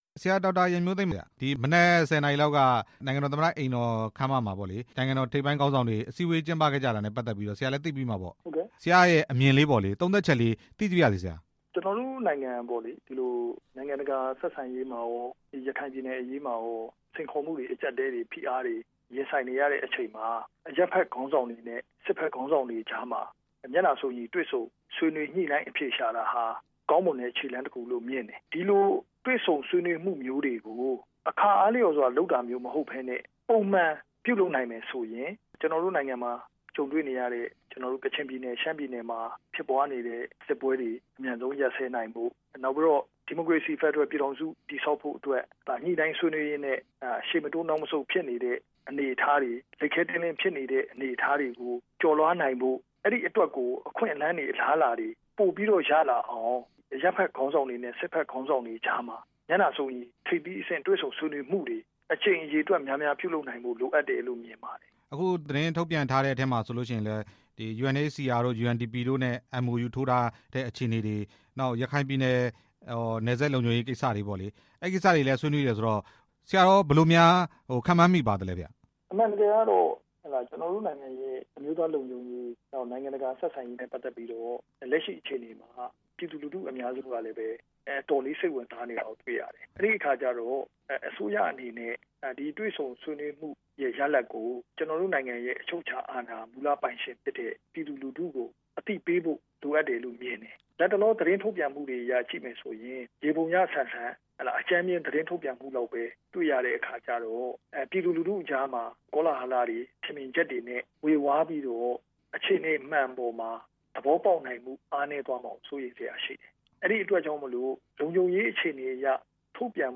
နိုင်ငံ့ခေါင်းဆောင်းတွေ တွေ့ဆုံတဲ့အကြောင်း မေးမြန်းချက်